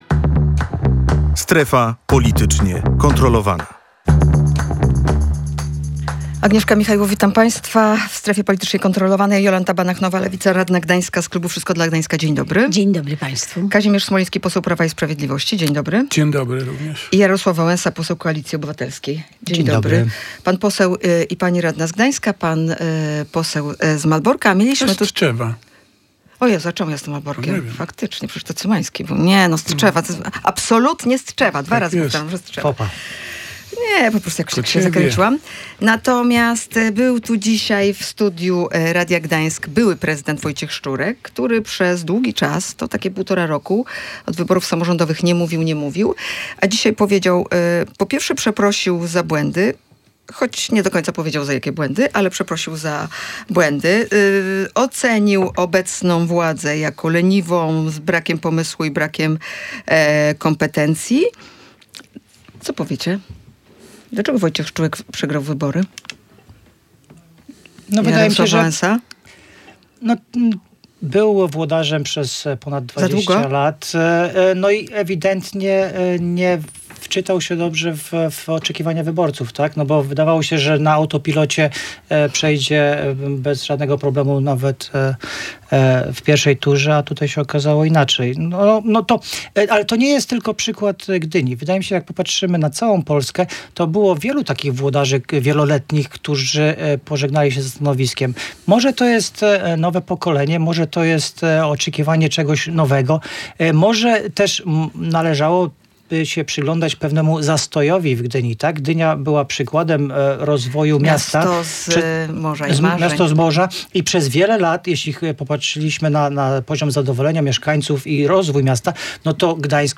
Trzeba rozmawiać z radami dzielnic i szukać sposobu ich finansowania - oceniają pomorscy politycy. O problemach dotyczących ich sytuacji rozmawiali: